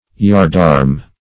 Yardarm \Yard"arm`\, n.